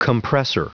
Prononciation du mot compressor en anglais (fichier audio)
Prononciation du mot : compressor